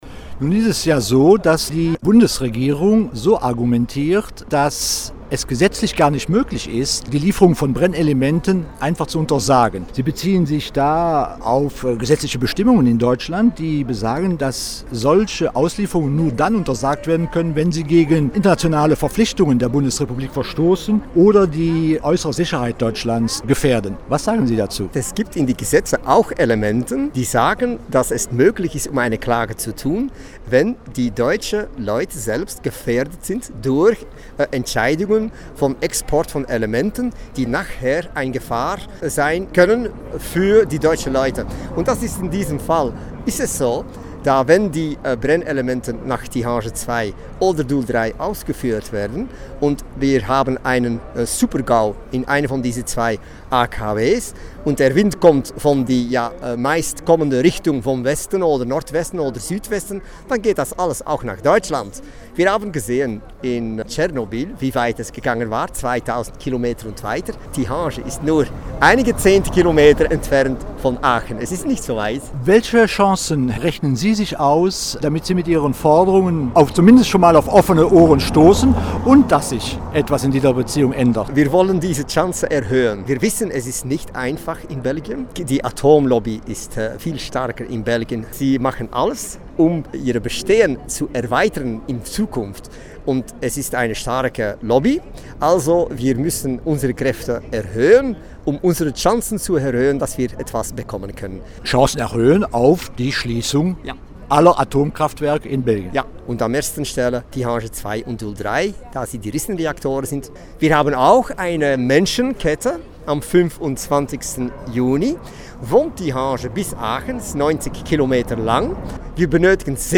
Am Freitag demonstrierte er zusammen mit einer Handvoll Aktivisten aus den Niederlanden und Deutschland vor der deutschen Botschaft in Brüssel.